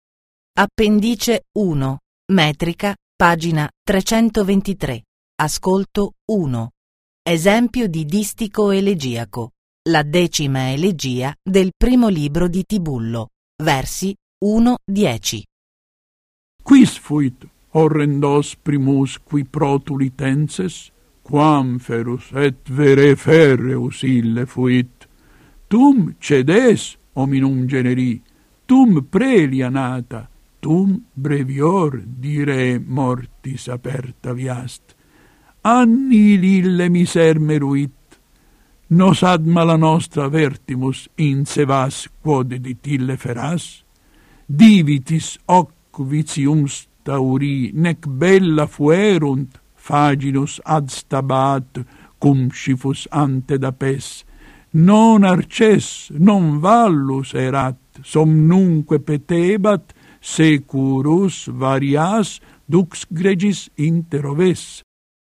Alcuni esempi di lettura ritmica di testi poetici contenuti nella Metrica possono essere ascoltati on line.
• Lettura ritmica di alcuni esempi della Metrica della Teoria